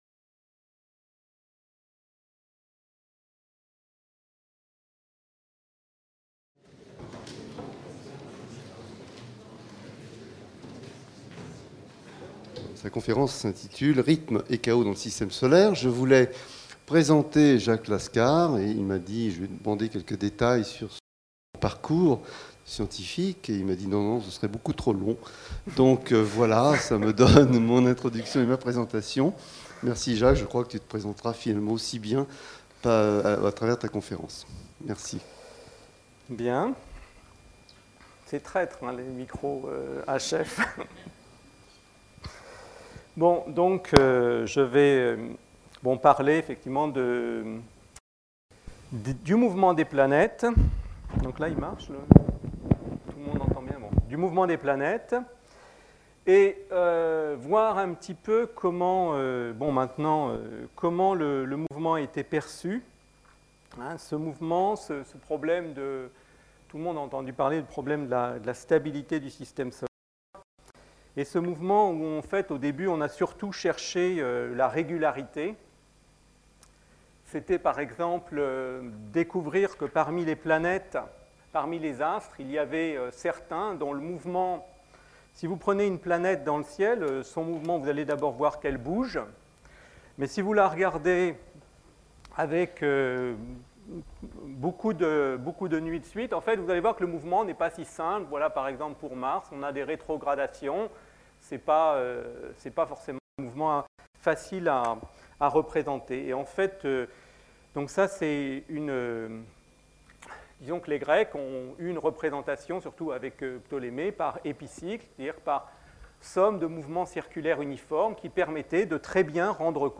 Conférence donnée par Jacques Laskar dans le cadre des conférences publiques de l'Institut d'astrophysique de Paris, le mardi 5 février 2002.